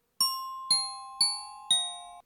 Guide des carillons
Diving_raven.ogg